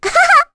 May-Vox-Laugh2.wav